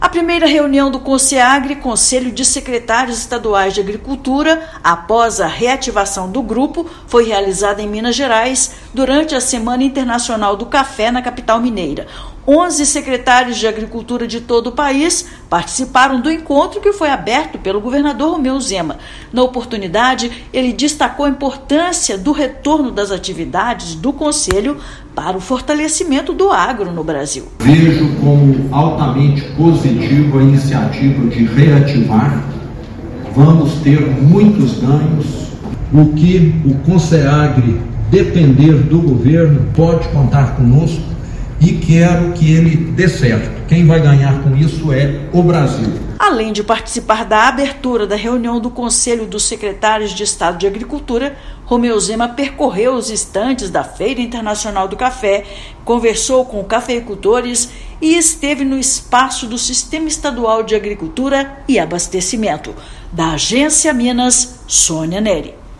[RÁDIO] Minas Gerais sedia retomada de encontro do Conselho de Secretários Estaduais de Agricultura
Reunião foi realizada durante a Semana Internacional do Café, em Belo Horizonte; reativação do Conseagri volta a colocar em pauta temas comuns ao agro de todos os estados. Ouça matéria de rádio.